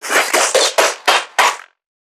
NPC_Creatures_Vocalisations_Infected [62].wav